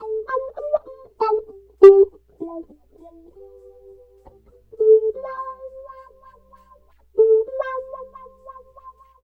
88 GTR 2  -L.wav